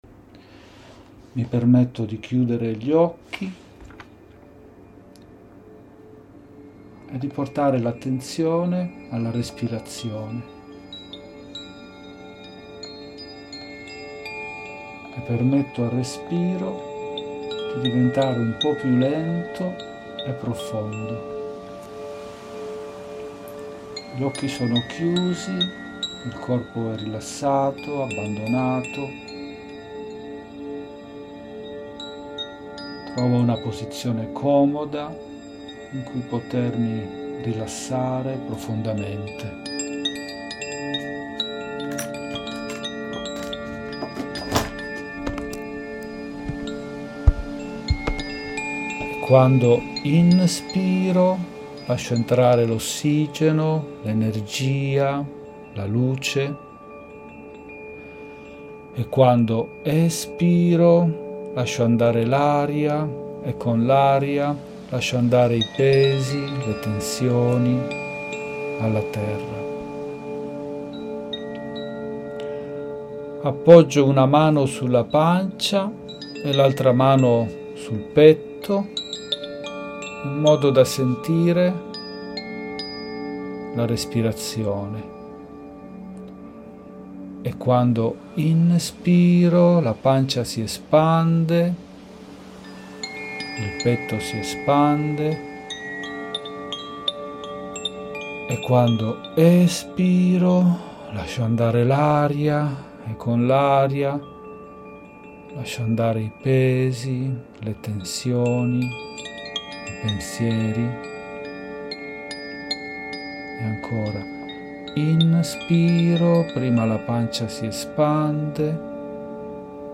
Meditazione per la Pace interiore e nel mondo con Visualizzazione guidata